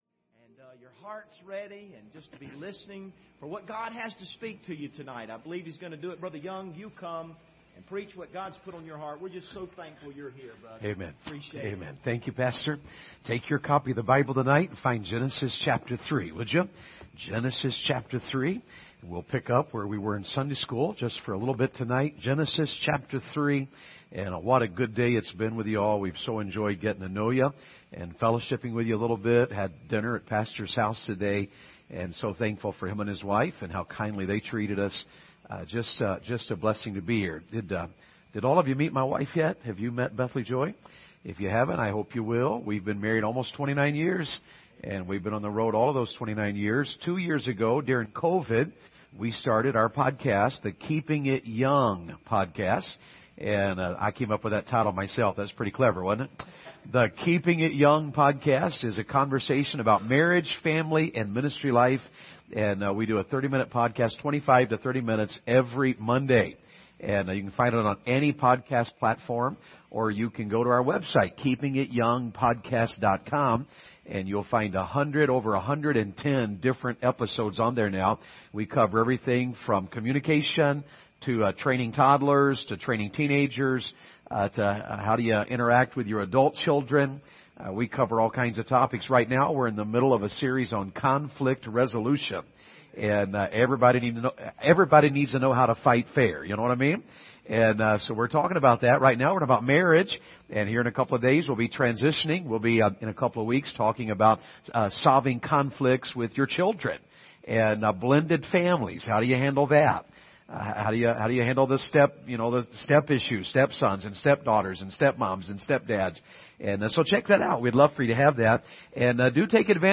Passage: Genesis 3:14-4:7 Service Type: Revival Service